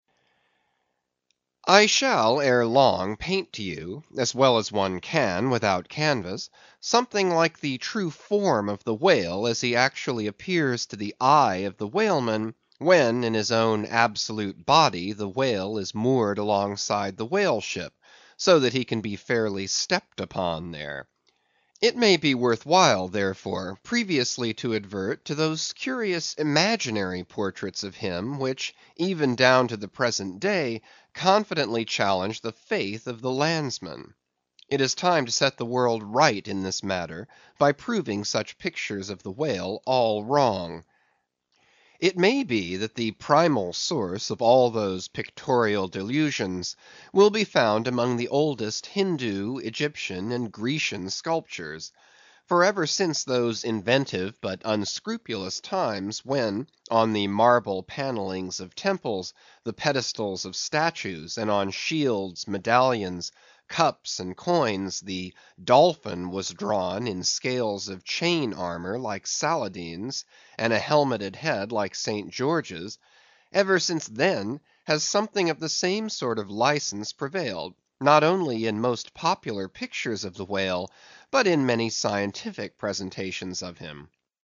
英语听书《白鲸记》第165期 听力文件下载—在线英语听力室